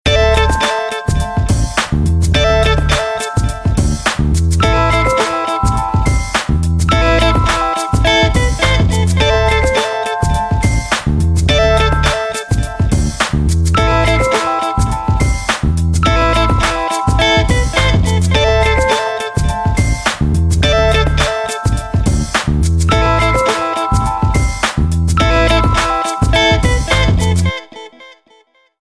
زنگ موبایل
ملودی نیمه ورزشی برای گوشی موبایل